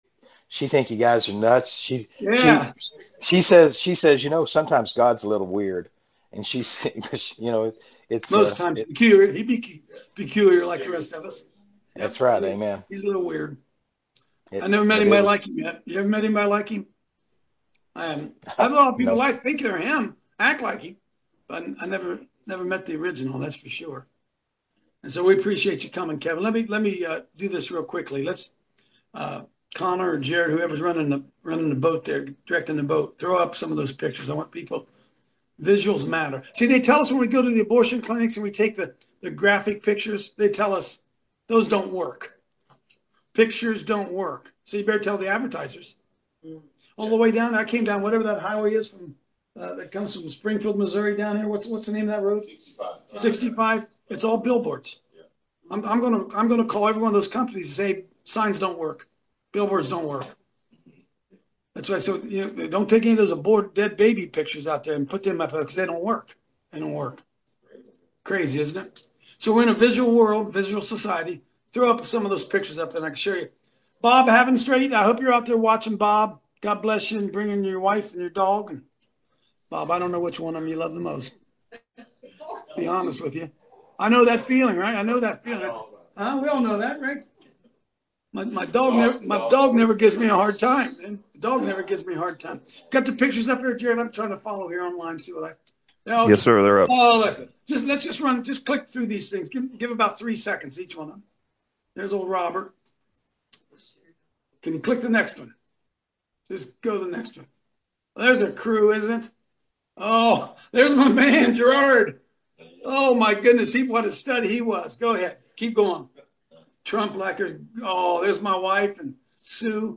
Today’s show is hosted from Branson, Missouri where we are on another tour stop for Equal Justice.